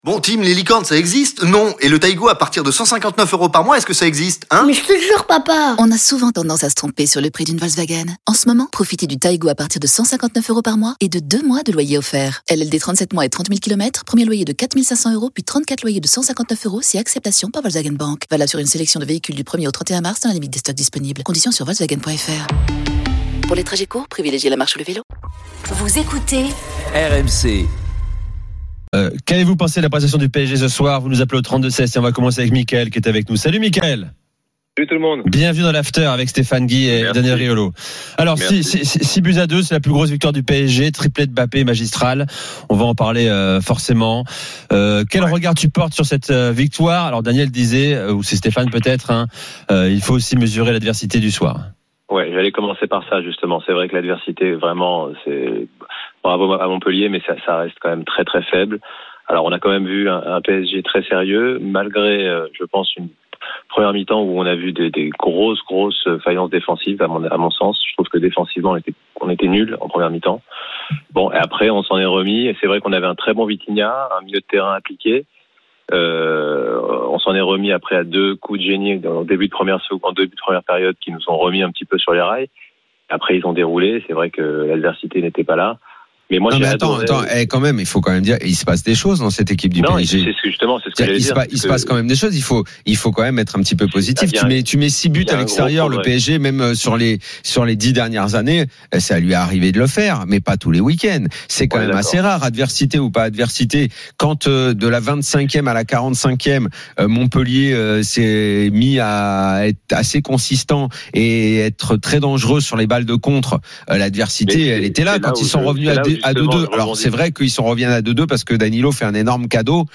L’After foot, c’est LE show d’après-match et surtout la référence des fans de football depuis 15 ans !
RMC est une radio généraliste, essentiellement axée sur l'actualité et sur l'interactivité avec les auditeurs, dans un format 100% parlé, inédit en France.